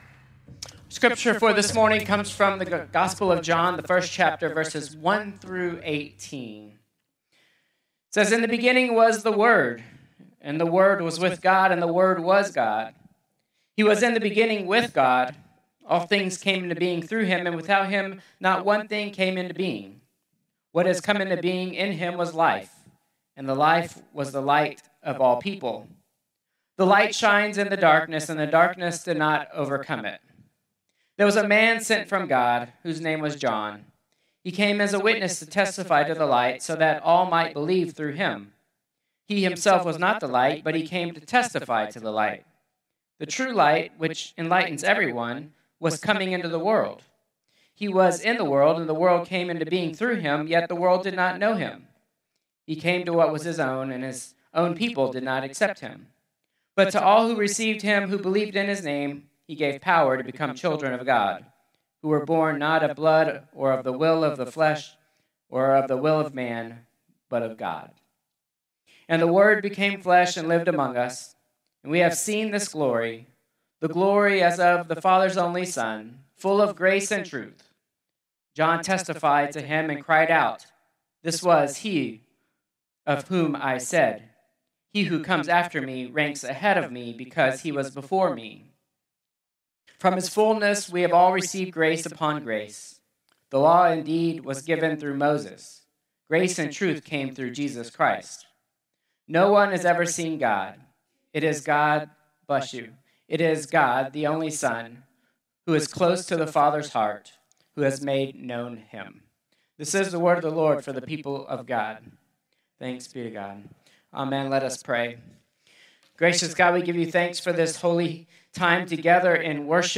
Contemporary Worship 1-4-2026